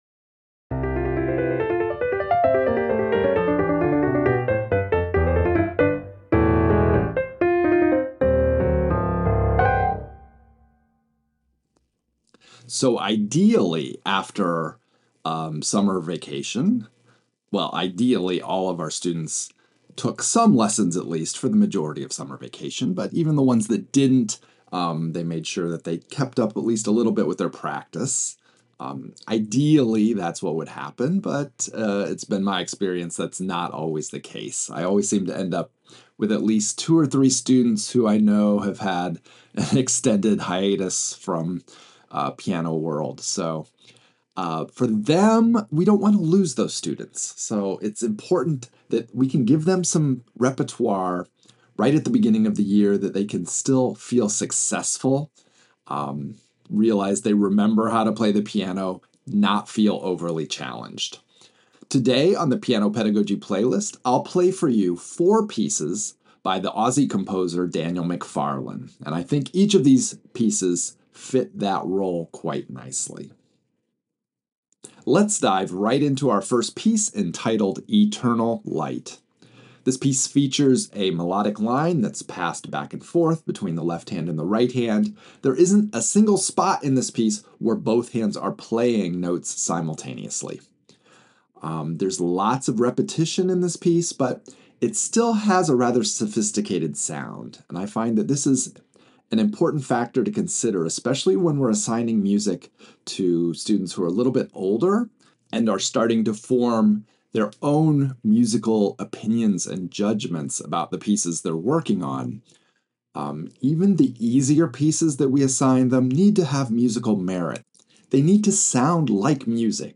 Late elementary to early intermediate piano solos